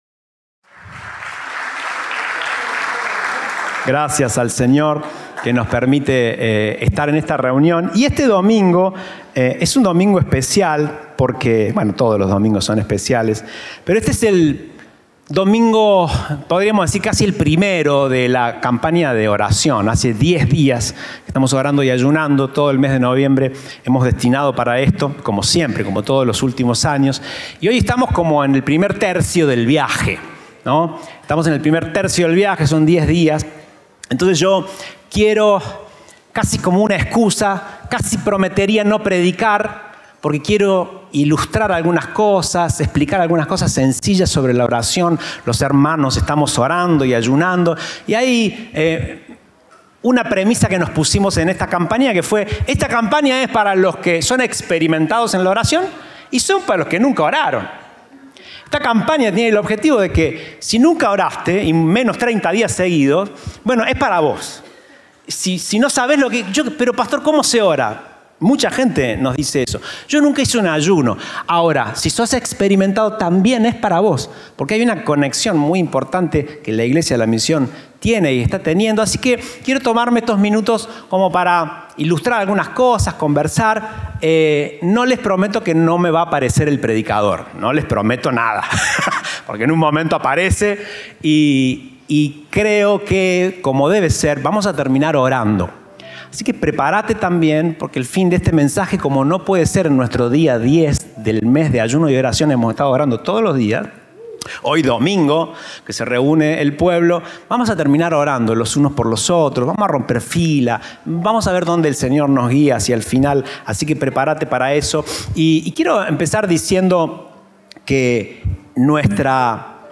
Compartimos el mensaje del Domingo 10 de Noviembre de 2024